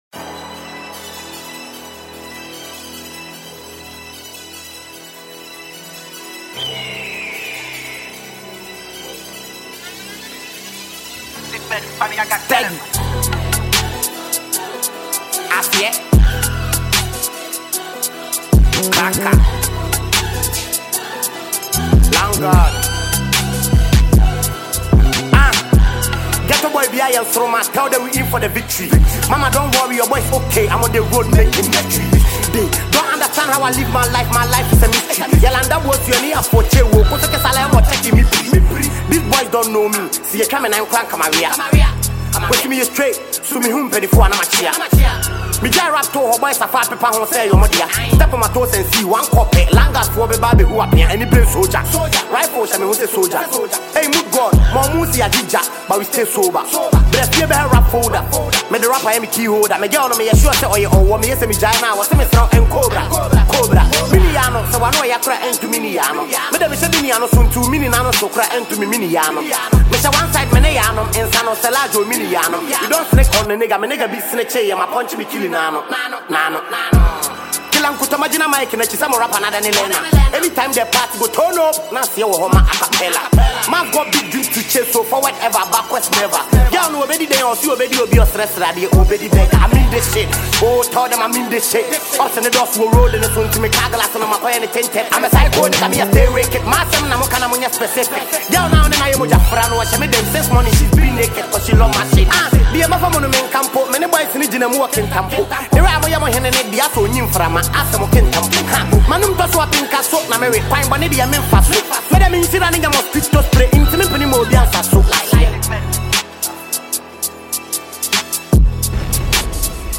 Ghanaian rapper and musician